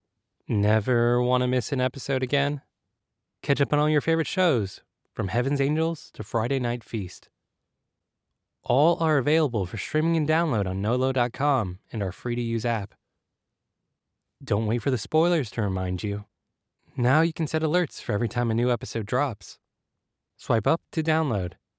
American Voice Over Talent
Adult (30-50) | Yng Adult (18-29)
Our voice over talent record in their professional studios, so you save money!